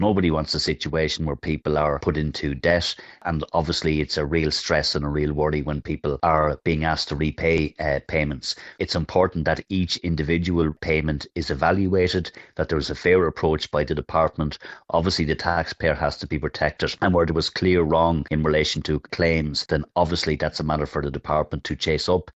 Sinn Fein TD David Cullinane, says it’s important a fair approach is taken when it comes to recouping money: